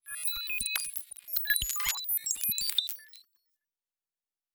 pgs/Assets/Audio/Sci-Fi Sounds/Electric/Data Calculating 3_5.wav at 7452e70b8c5ad2f7daae623e1a952eb18c9caab4
Data Calculating 3_5.wav